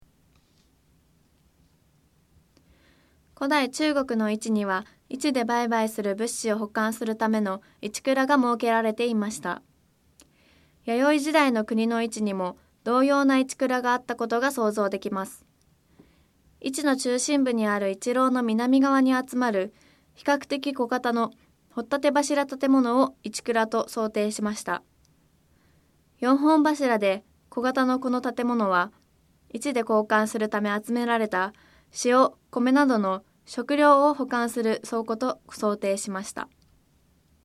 4本柱で小型のこの建物は、市で交換するため集められた塩、米などの食料を保管する倉庫と想定しました。 音声ガイド 前のページ 次のページ ケータイガイドトップへ (C)YOSHINOGARI HISTORICAL PARK